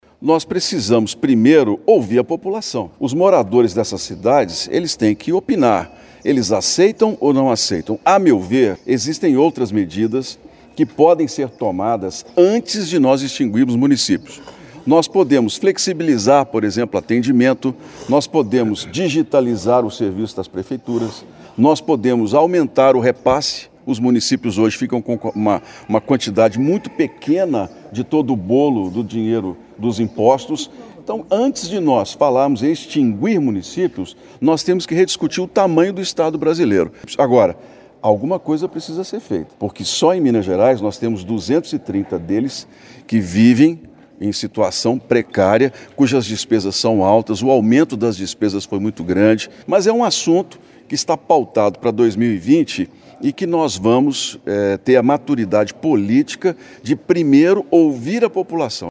O senador foi recebido ainda no aeroporto de Ubaporanga, onde falou com a imprensa.